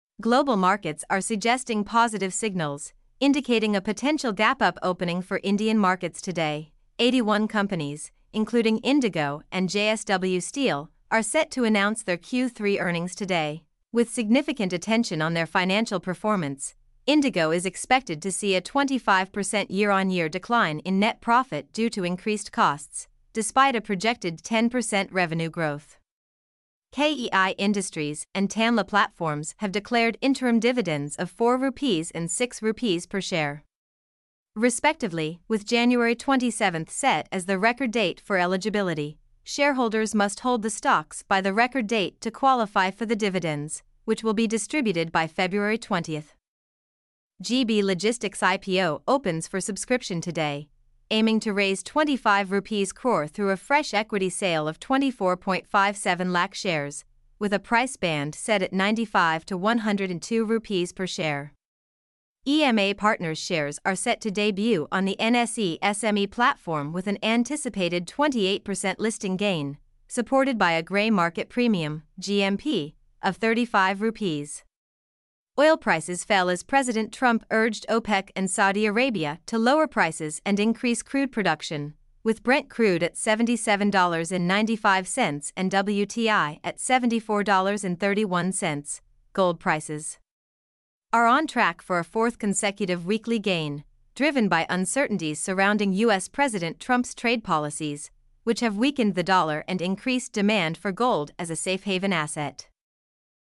mp3-output-ttsfreedotcom-1-1.mp3